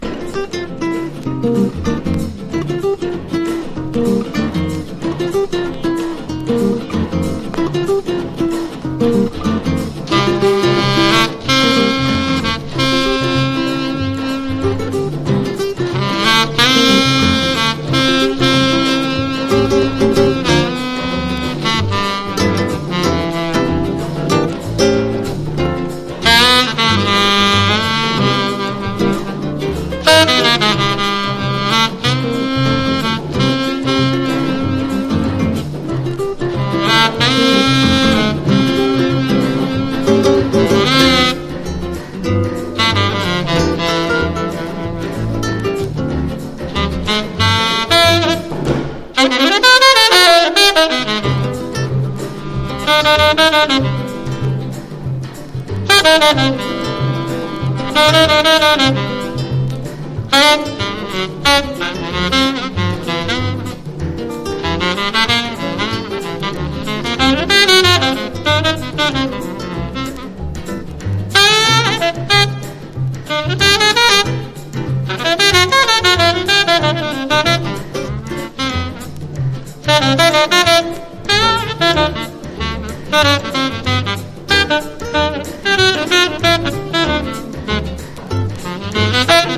存在感のあるサックスとギターのカッティングが気持ち良いです。
# LATIN